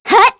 Aselica-Vox_Attack4_kr.wav